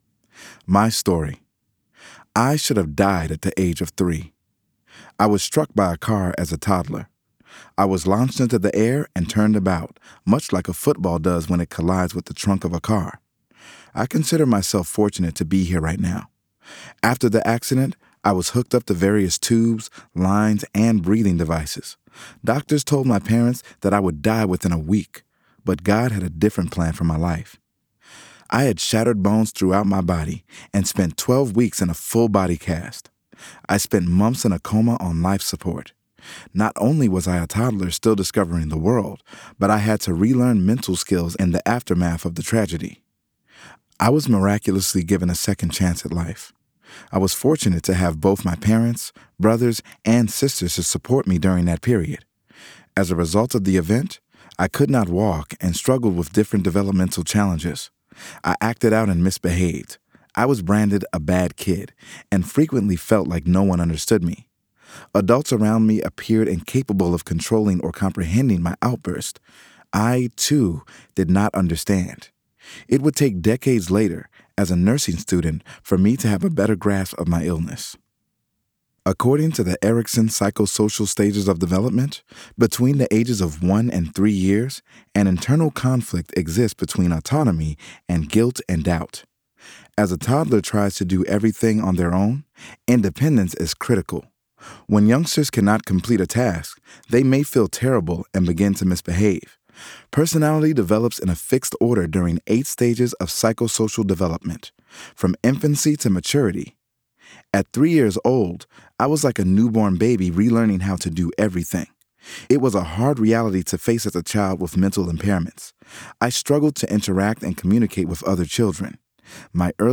***Audio Book*** Save Yourself: 5 Common Disease With Prevention